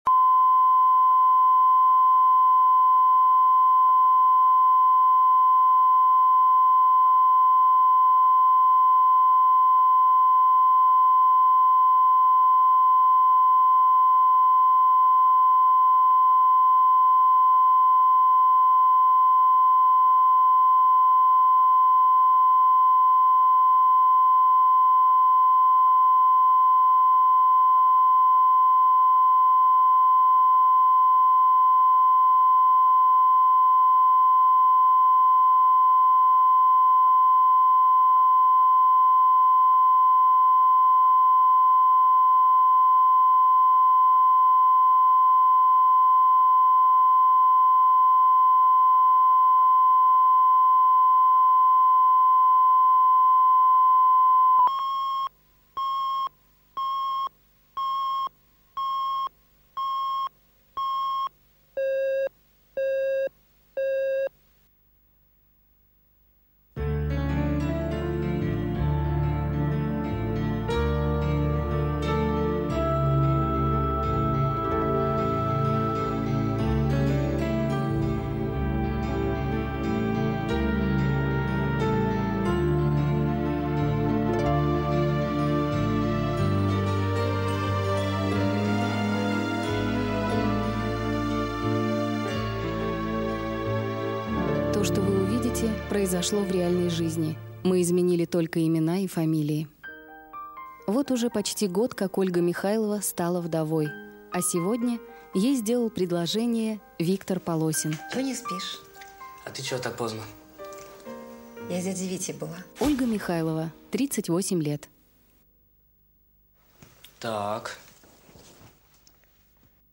Аудиокнига Когда гаснет солнце | Библиотека аудиокниг